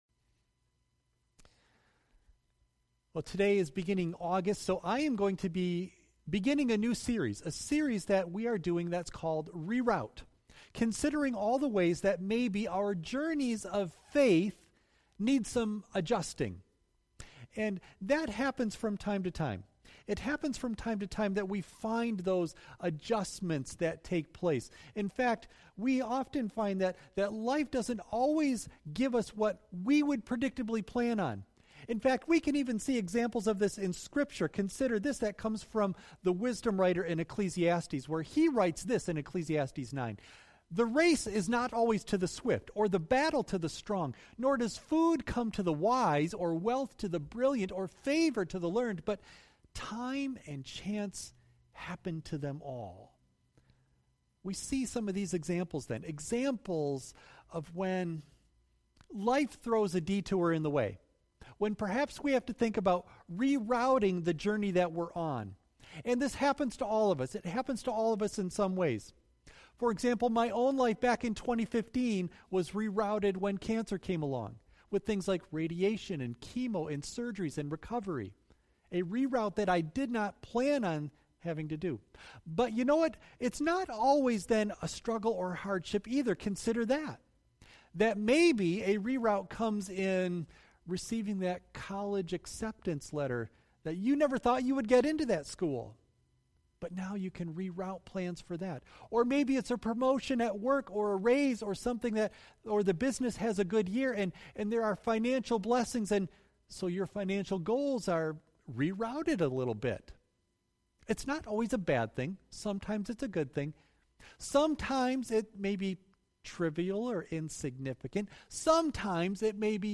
Audio only of message